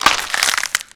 break4.ogg